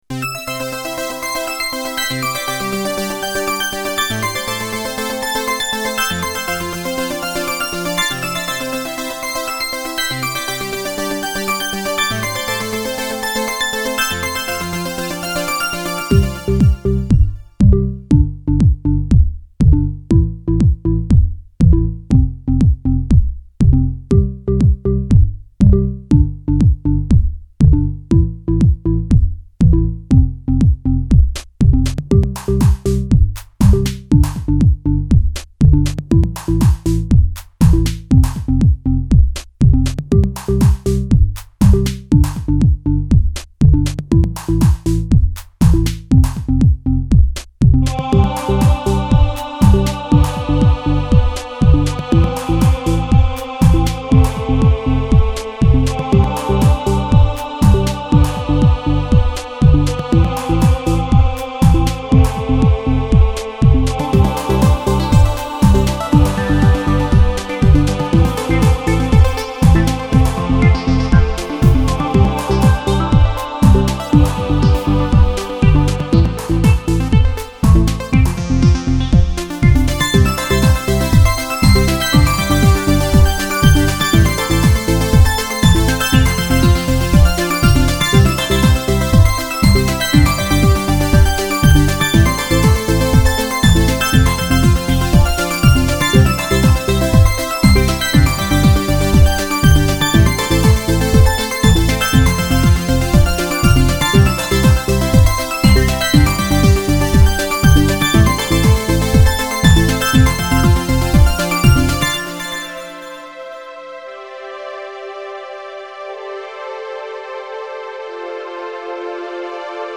04:15 Electronica 4.4 MB